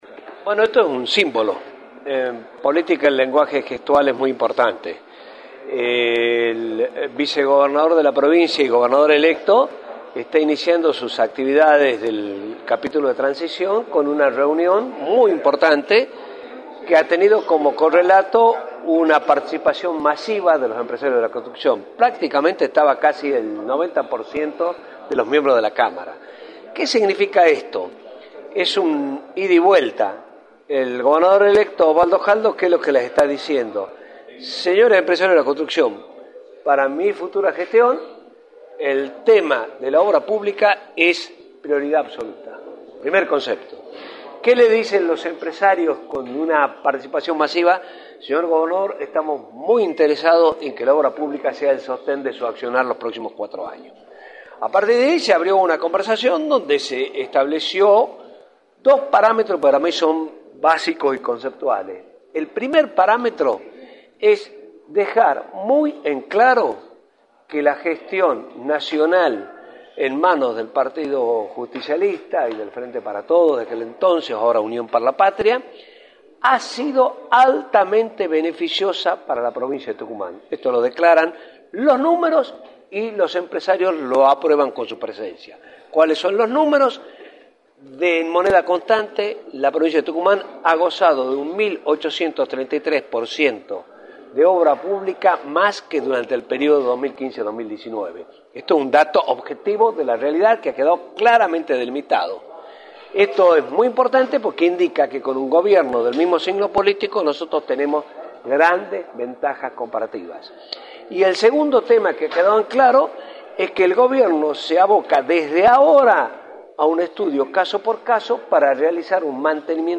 Sisto Terán, Secretario de la Unidad Belgrano Norte Grande, analizó en Radio del Plata Tucumán, por la 93.9, las repercusiones de la reunión que mantuvo el Vicegobernador Osvaldo Jaldo con el sector empresarial de la construcción.